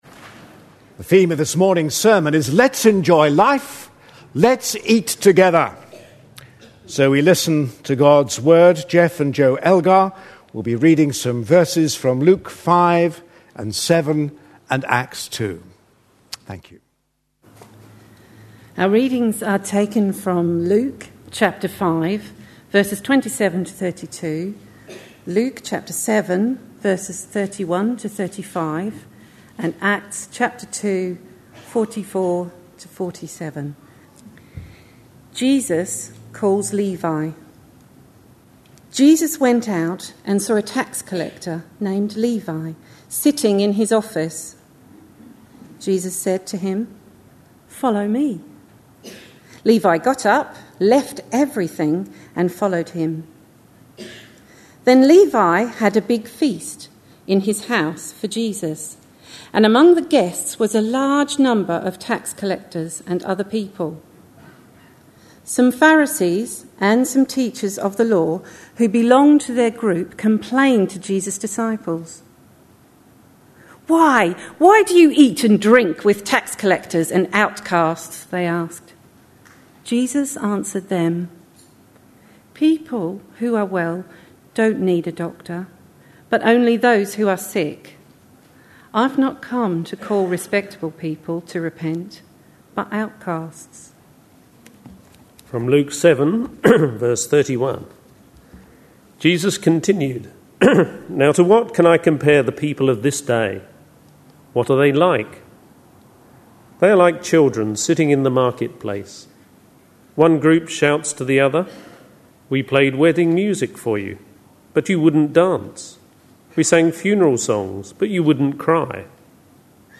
A sermon preached on 29th August, 2010.